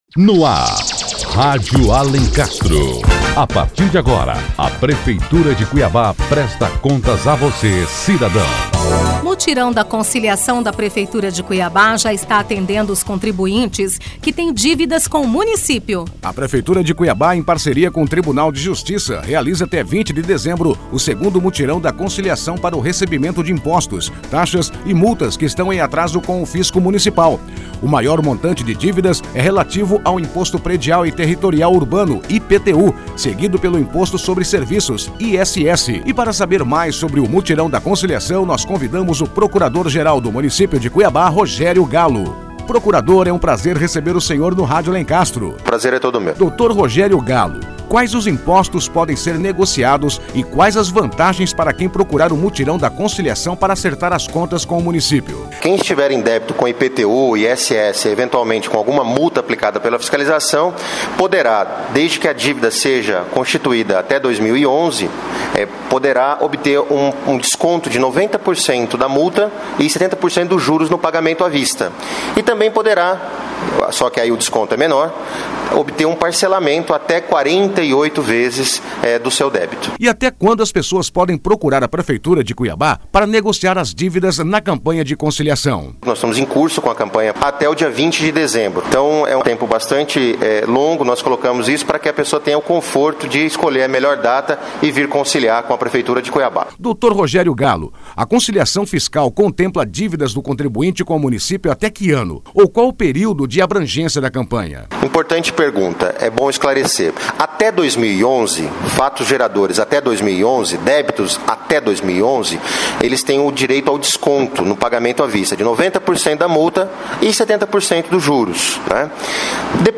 Para saber mais sobre Mutirão da Conciliação convidamos o Procurador Geral do Município, Rogério Gallo. Confira a entrevista.